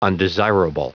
Prononciation du mot undesirable en anglais (fichier audio)
Prononciation du mot : undesirable